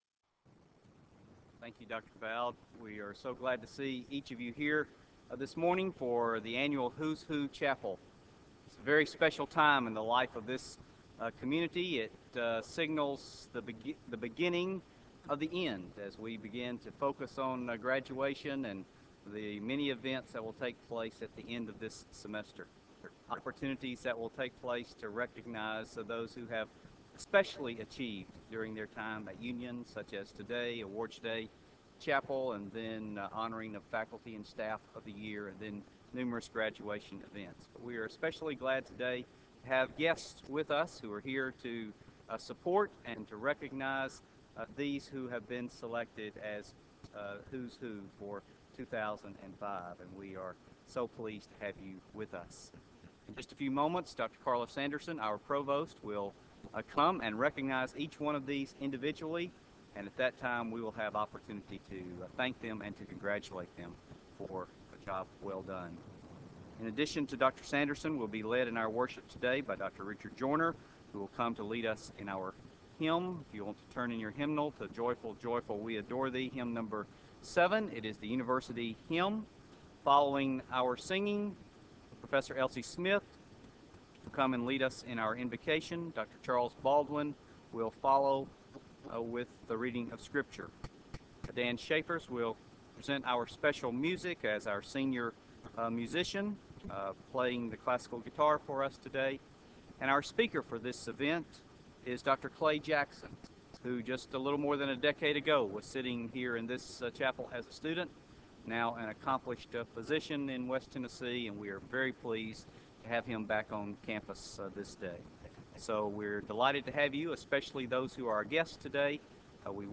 Who's Who Chapel Service